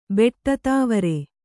♪ beṭṭa tāvare